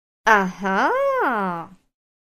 Звуки согласия
Все файлы записаны четко, без фоновых шумов.
Женщина красиво говорит Ага